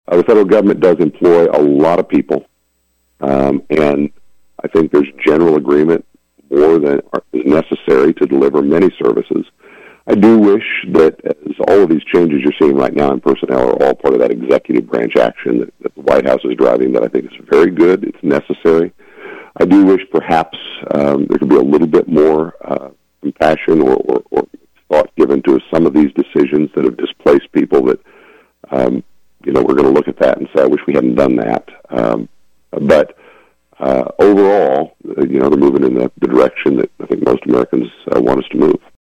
Friday’s interview was the first of Schmidt’s monthly updates on KVOE.